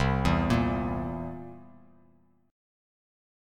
C7 Chord
Listen to C7 strummed